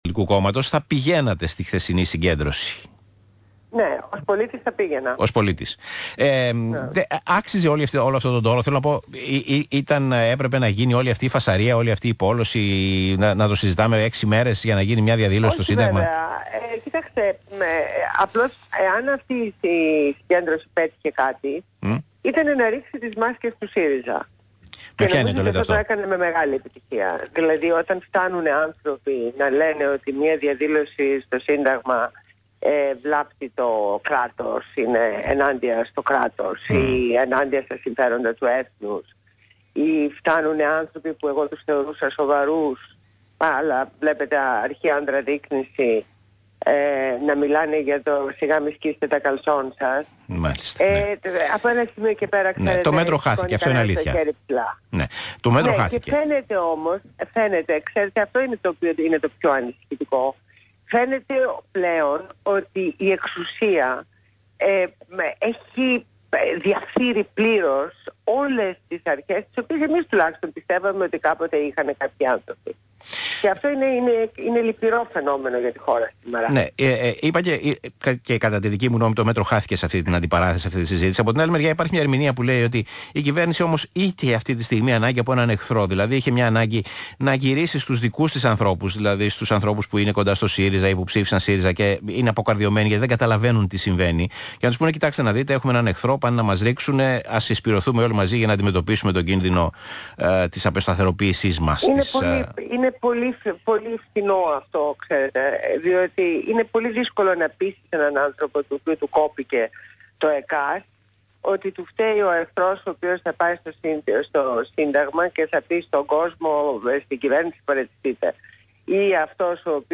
Συνέντευξη στο ραδιόφωνο του ΣΚΑΙ.
Συνέντευξη στο ραδιόφωνο του ΣΚΑΙ στο δημοσιογράφο Π. Τσίμα.